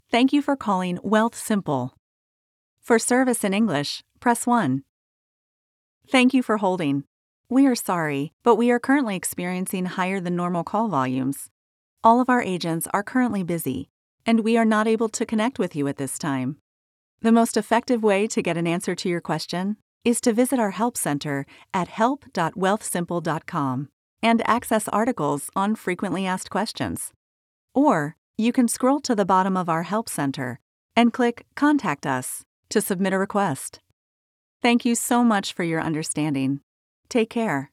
Telephony
PROFESSIONAL HOME STUDIO
Vocal booth
IVR-Call-Center-friendly-warm-helpful.mp3